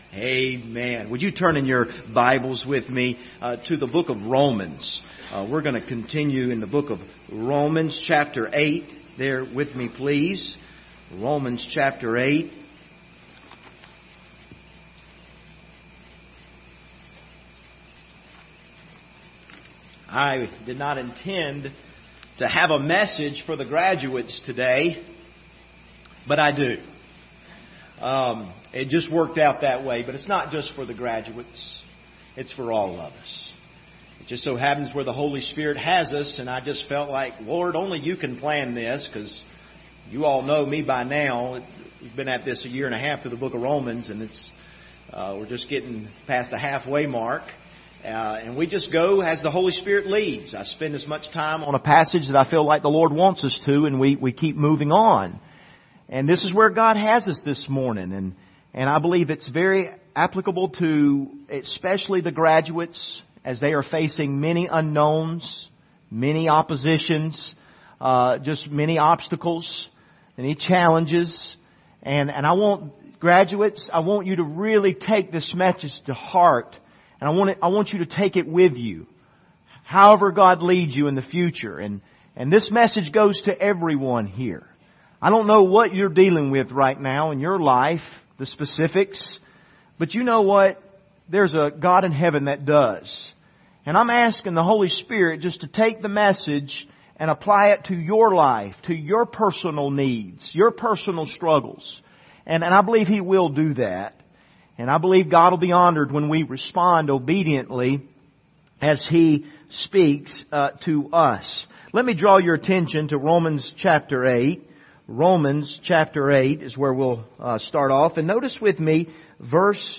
Passage: Romans 8:26-31 Service Type: Sunday Morning